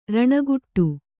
♪ raṇaguṭṭu